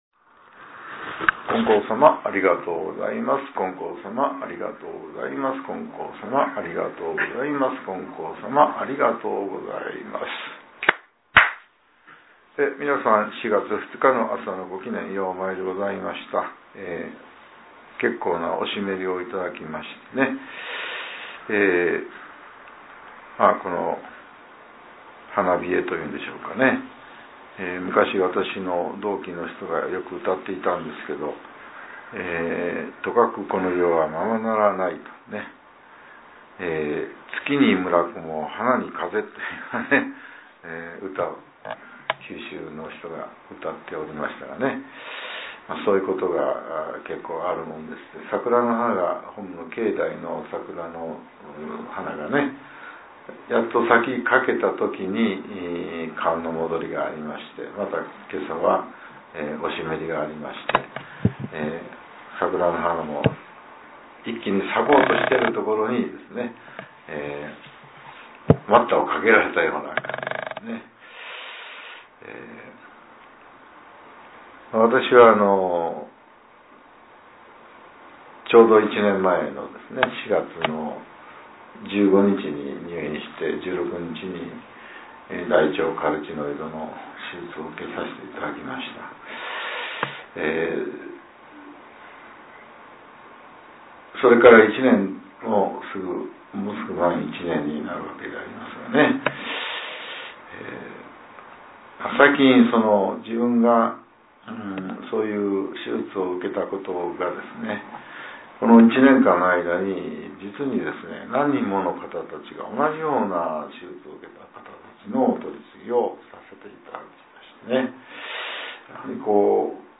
こちらは、元日祭の祭詞と、ことしの当教会の祈願詞です。